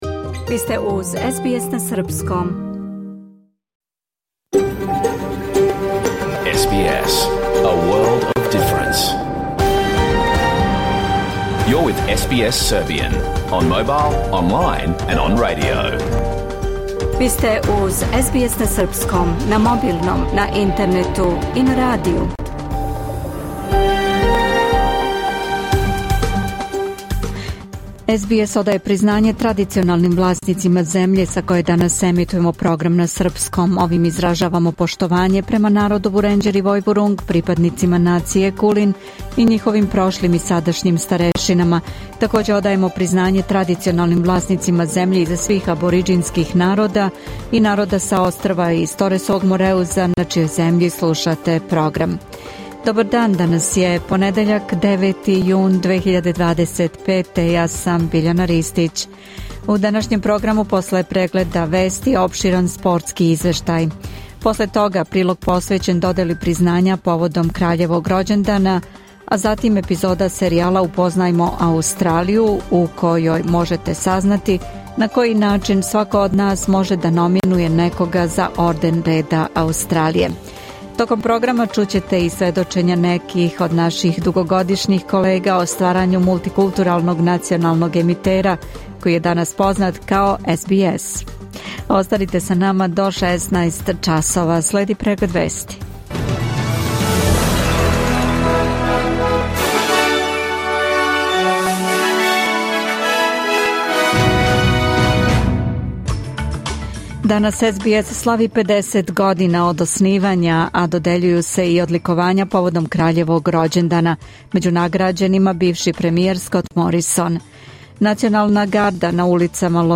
Програм емитован уживо 9. јуна 2025. године